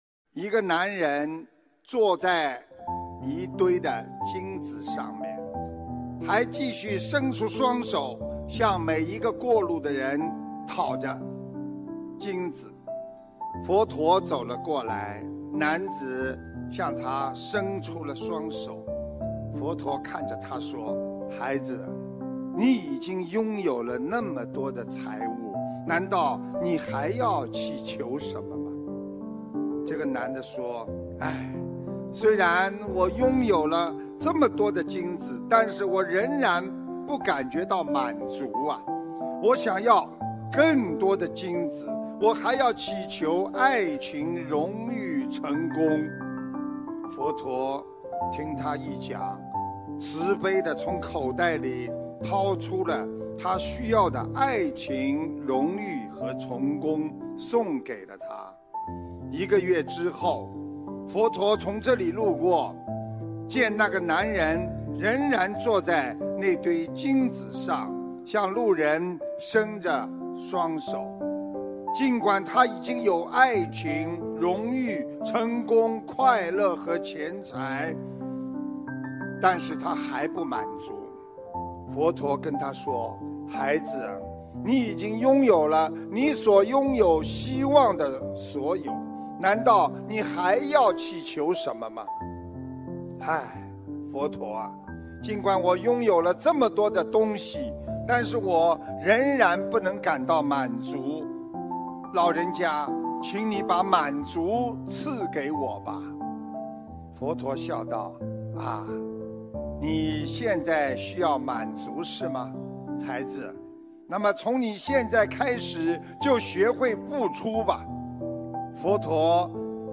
音频：师父讲故事～一个男人坐在一堆金子上面  还继续伸出双手向每一个过路的人讨金子，佛陀对他说......